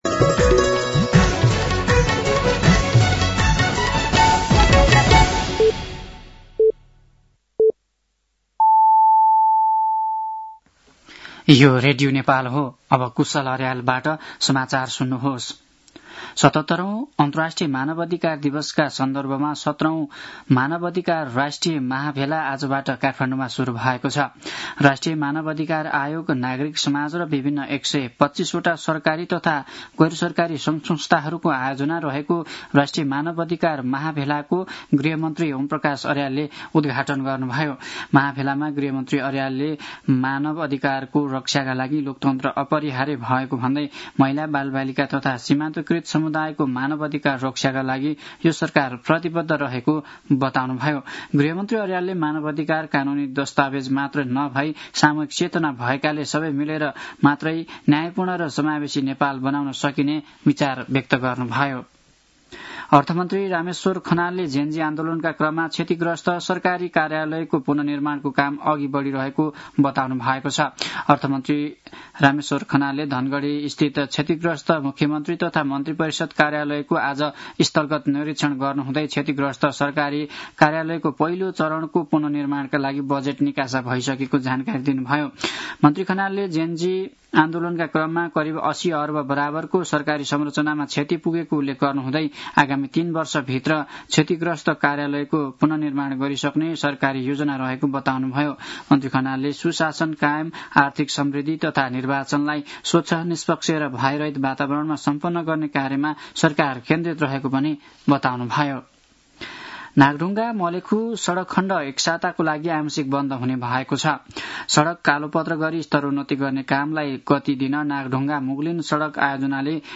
साँझ ५ बजेको नेपाली समाचार : २२ मंसिर , २०८२
5.-pm-nepali-news-1-1.mp3